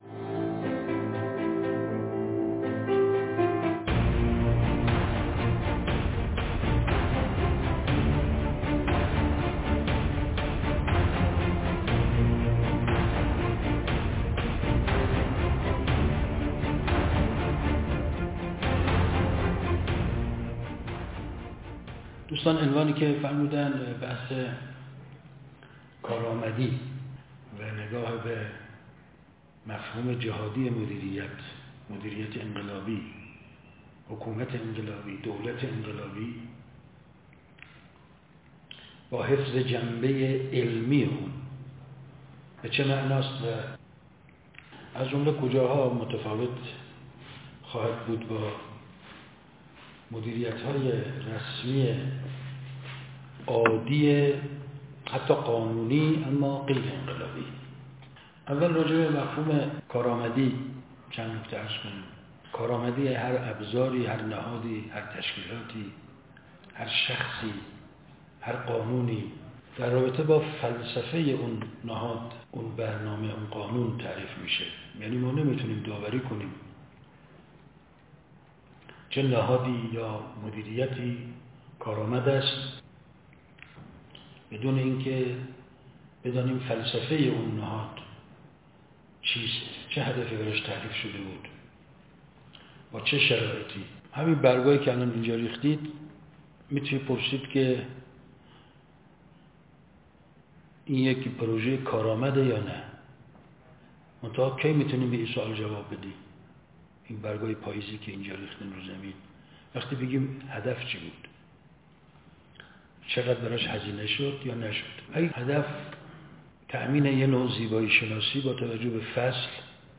نشست ('دولت‌سازی' مردمی در بستر 'نظام انقلابی') _ ۱۳۹۵